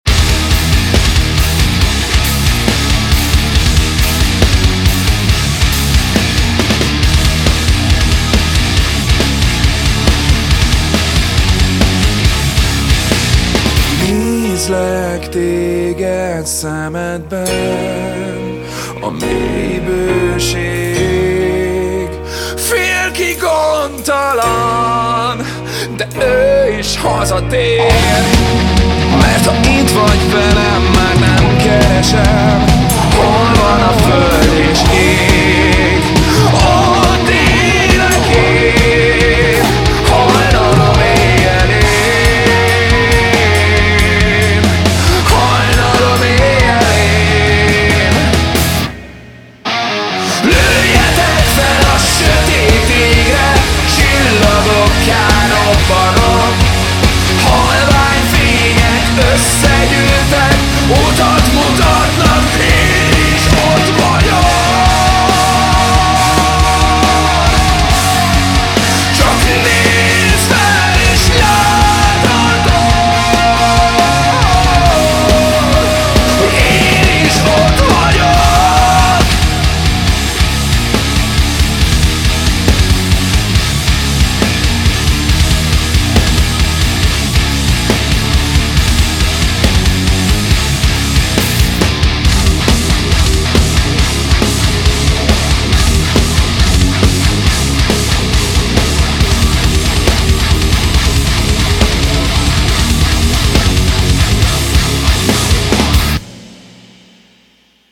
BPM138
Audio QualityPerfect (High Quality)
Comments[HEAVY METAL]